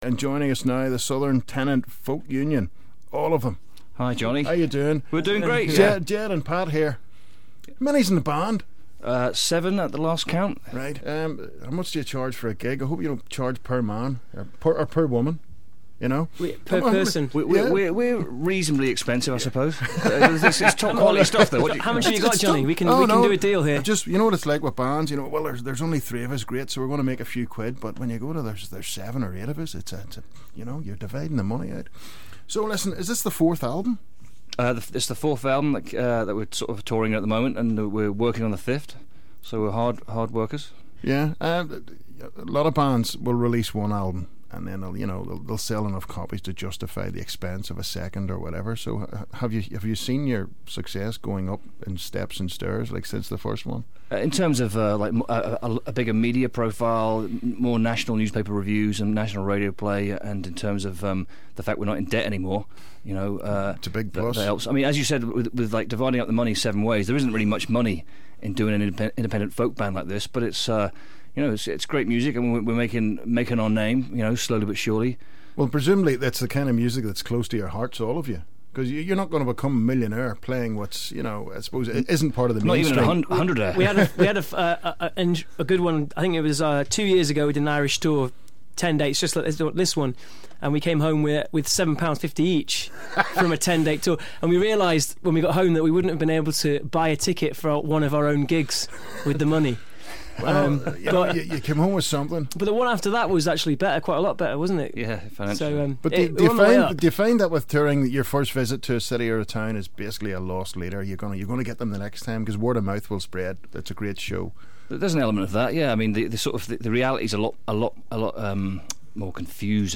Folk group